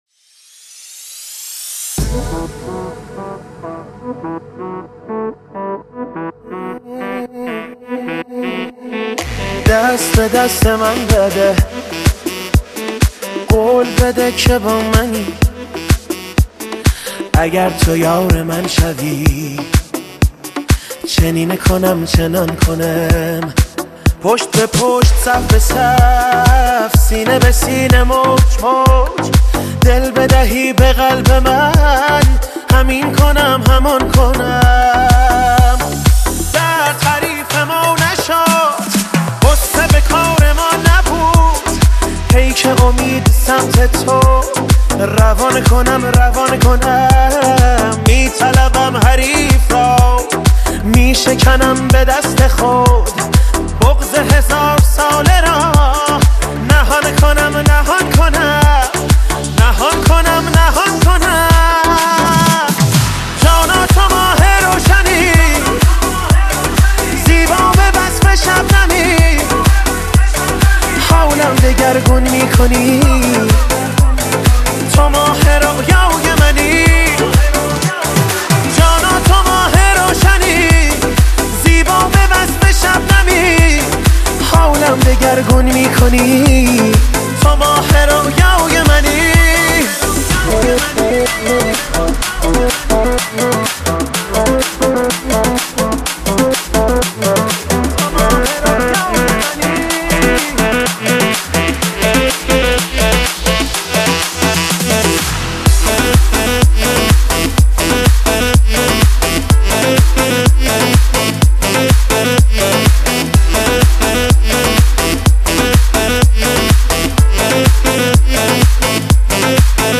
تك آهنگ ایرانی
شاد